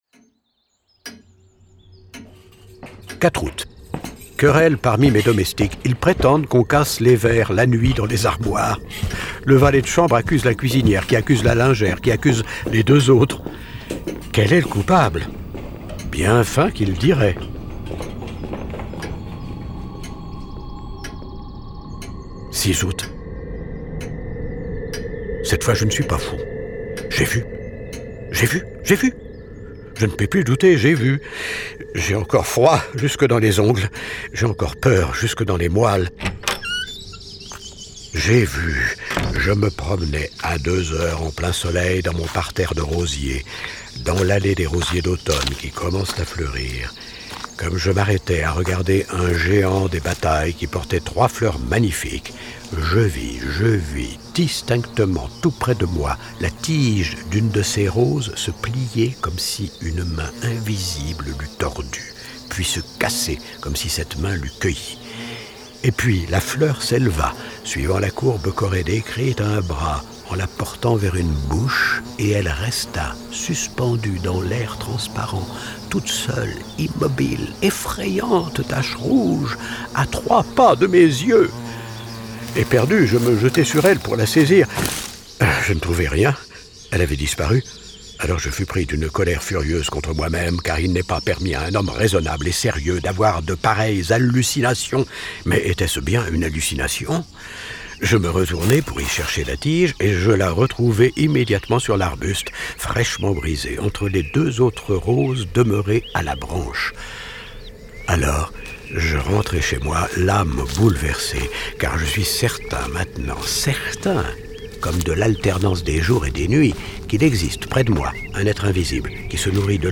sonorisé dans une mise en scène implacable